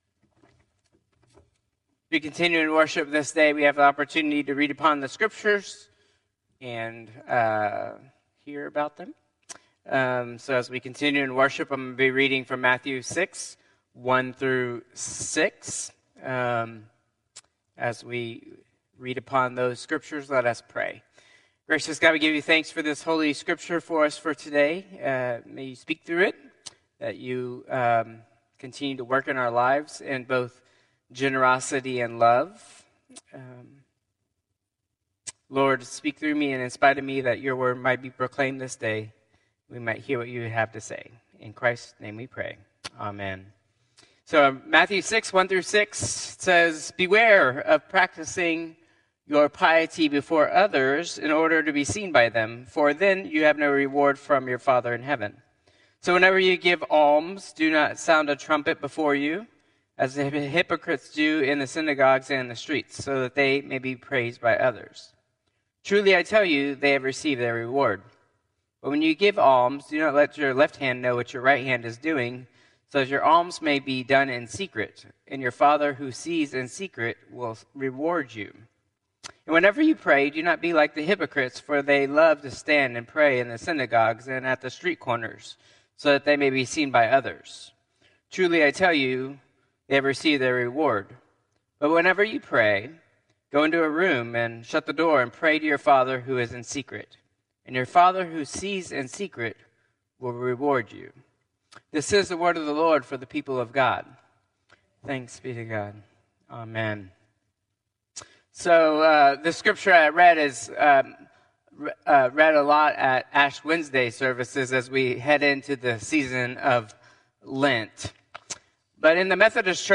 Traditional Service 10/20/2024